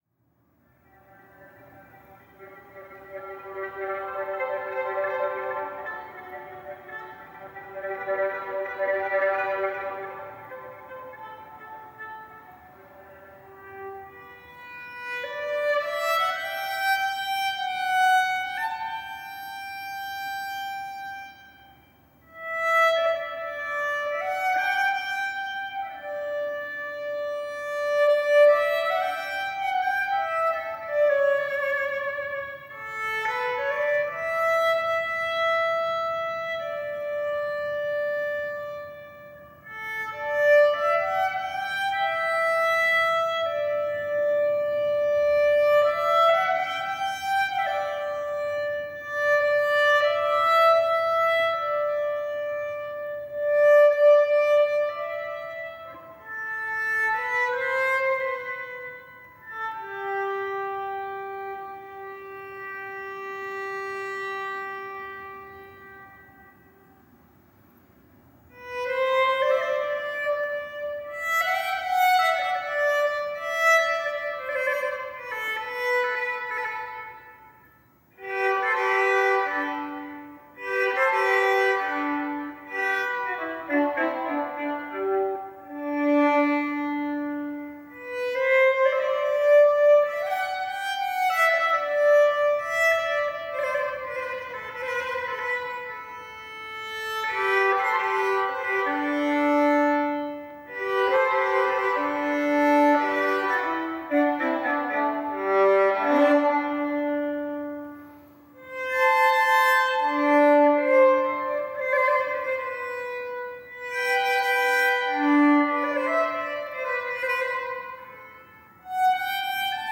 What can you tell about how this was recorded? St Botolph without Bishopsgate Rehearsal recordings (lo-fi Camera Rip)